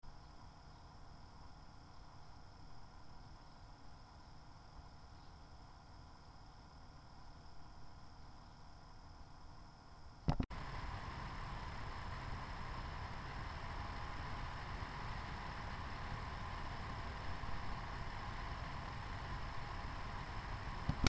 A mérést egy nagyjából 20 °C hőmérsékletű helyiségben végeztük, miközben a konfiguráció egy asztalon volt összeállítva.
Erről egy hangfelvételt is készítettünk, melynek rögzítés során a diktafon nagyjából 15 centiméterre volt a ventilátortól. A felvétel első 10 másodpercében az üresjárati, míg második 10 másodpercében a terhelés alatti zaj hallható: GTX 670,